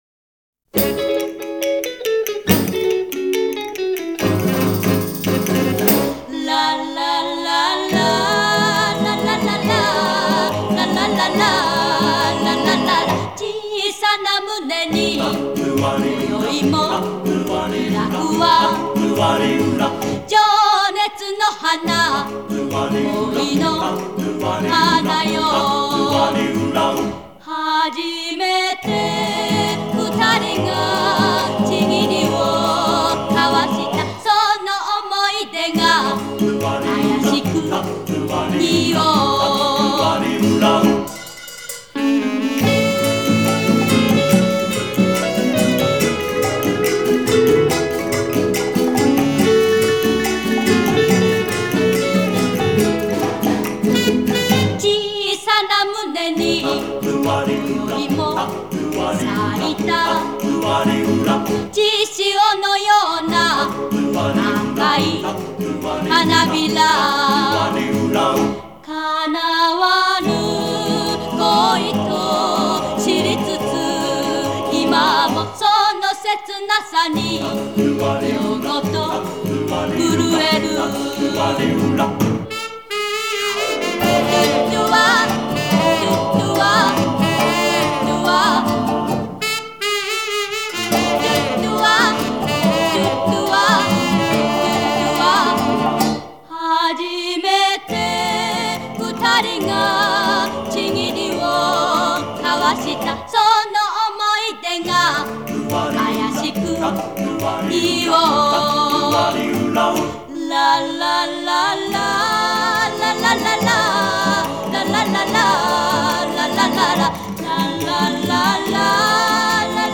Звук неплохой. Японский ремастеринг.
Жанр: Classic Pop